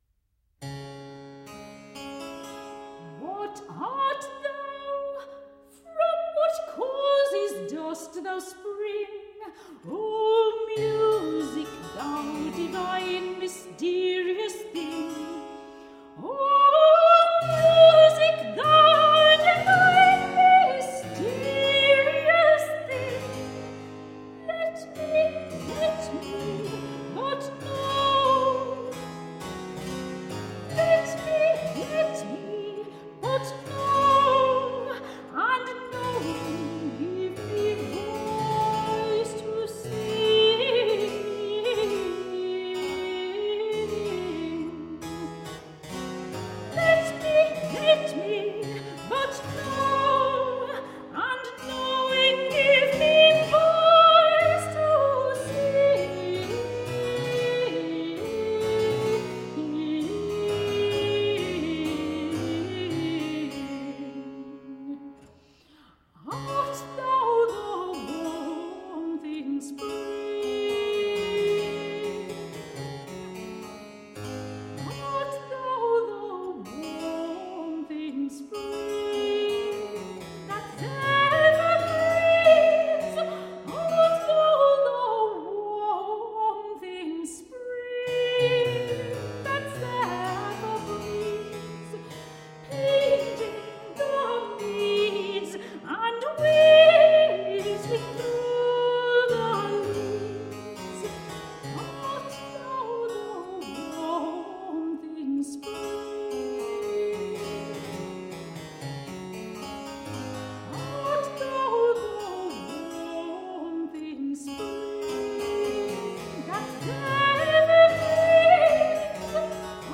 performed by the radiant clear soprano
Classical, Renaissance, Baroque, Classical Singing